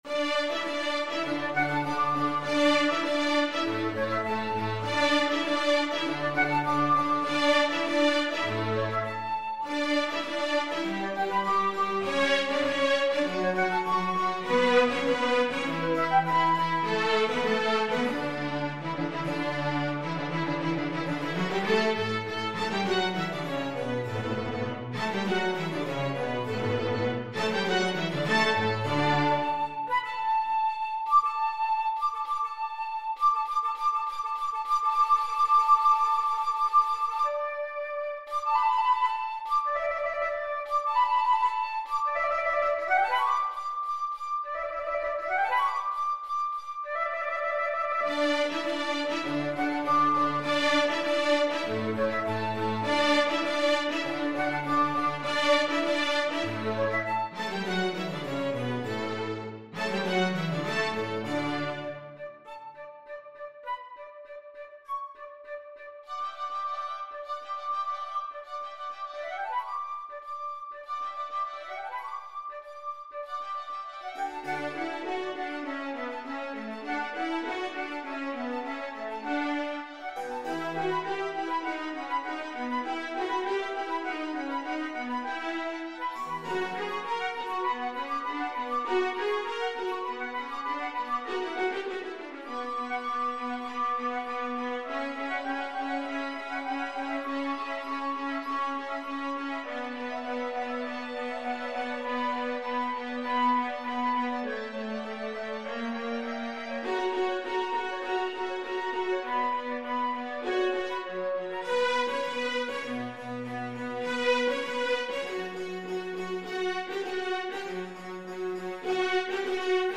Solo FluteViolin 1Violin 2ViolaCelloKeyboard
4/4 (View more 4/4 Music)
~ = 100 I: Allegro (View more music marked Allegro)
Classical (View more Classical Flute and Ensemble Music)